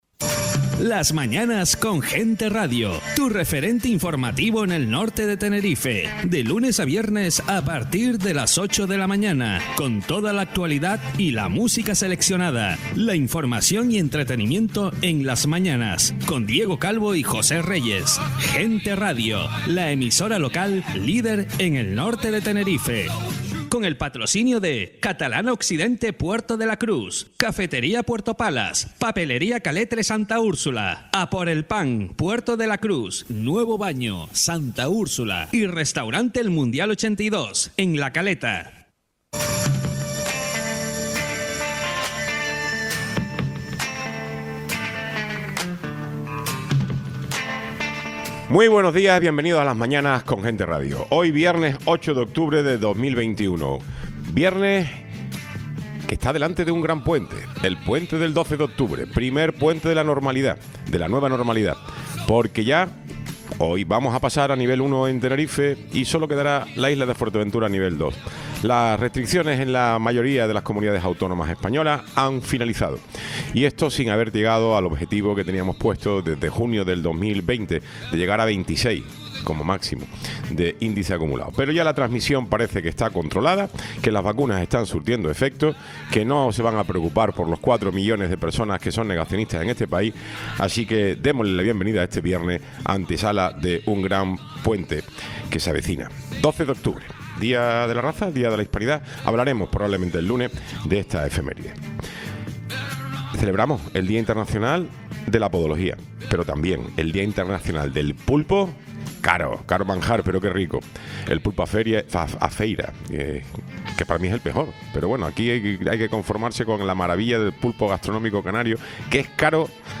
Tiempo de entrevista
Tertulia de mujeres políticas